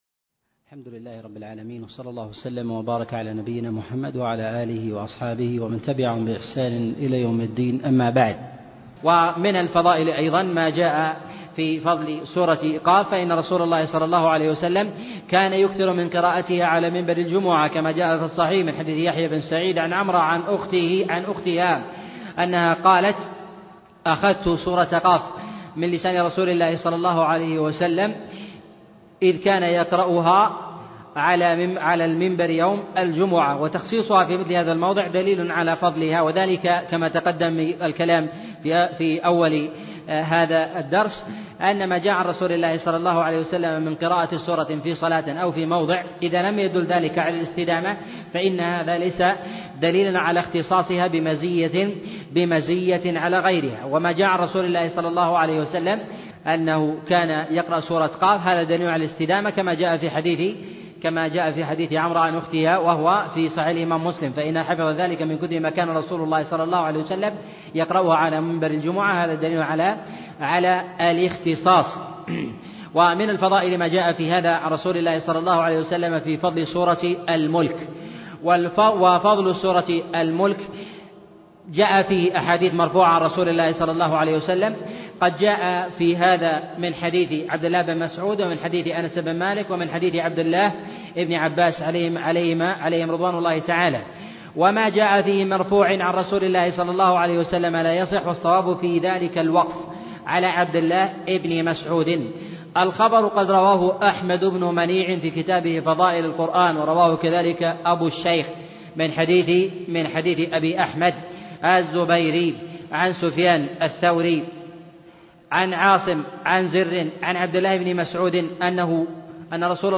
أرشيف الإسلام - أرشيف صوتي لدروس وخطب ومحاضرات الشيخ عبد العزيز الطريفي